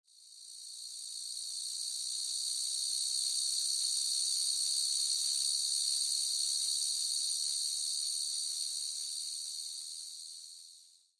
دانلود صدای حشره 22 از ساعد نیوز با لینک مستقیم و کیفیت بالا
جلوه های صوتی